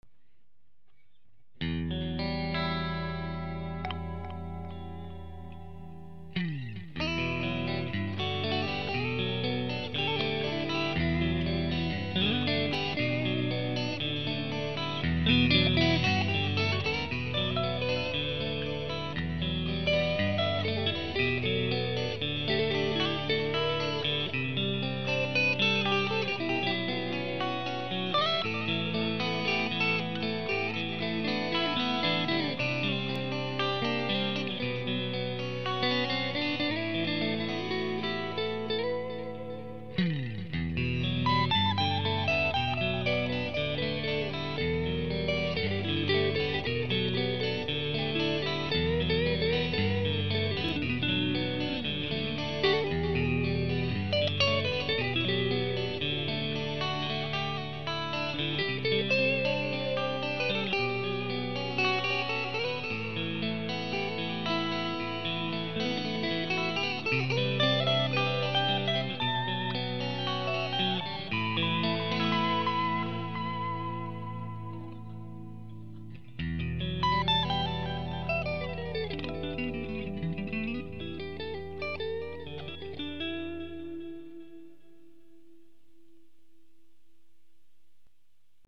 Son clair (arpèges et & solo) la gratte plantée dans le Blues Deluxe + un Delay (un peu trop ok ok ok ops
Deluxe_Clean_Sample.mp3